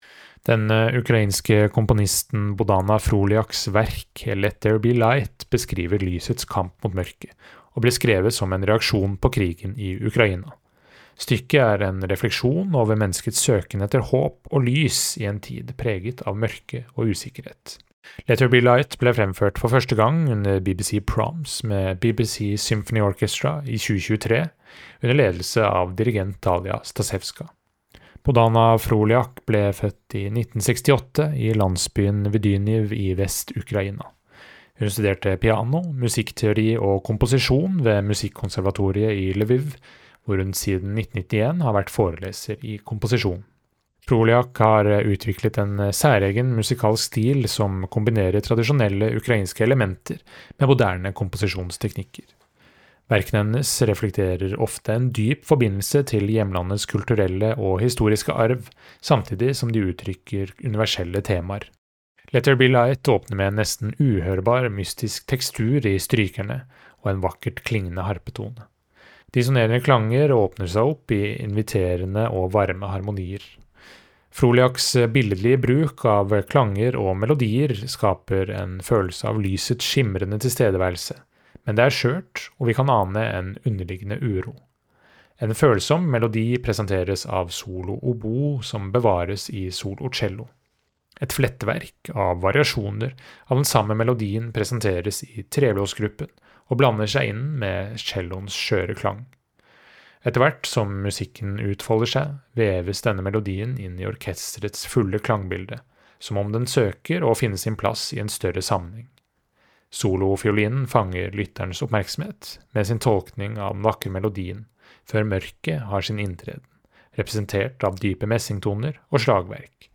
VERKOMTALE: Bohdana Frolyaks Let There Be Light Den ukrainske komponisten Bohdana Frolyaks verk, Let There Be Light, beskriver lysets kamp mot mørket, og ble skrevet som en reaksjon på krigen i Ukraina.